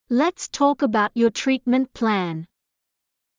ﾚｯﾂ ﾄｰｸ ｱﾊﾞｳﾄ ﾕｱ ﾄﾘｰﾄﾒﾝﾄ ﾌﾟﾗﾝ